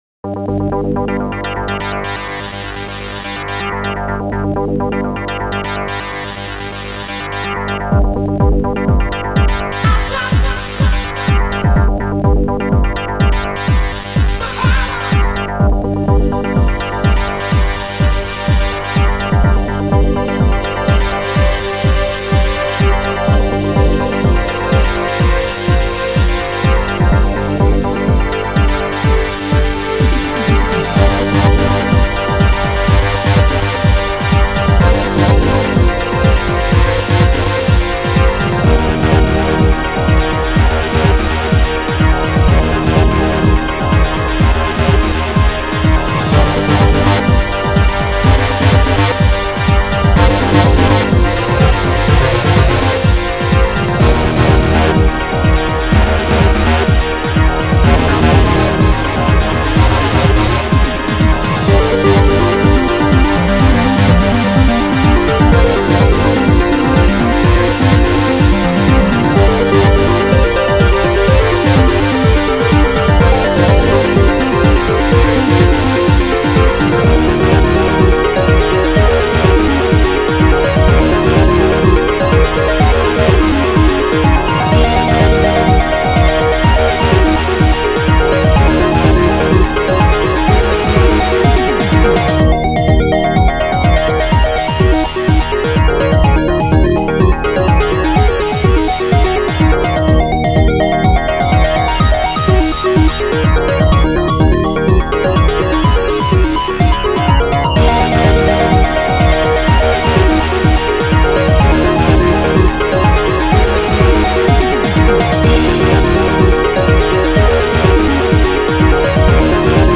s3m (Scream Tracker 3)
Brass Mayjur
Electro-tom
Tekno Beet
Cymbal sweep
Hihat Closed
Crash Cymbal
Korg Synth Lead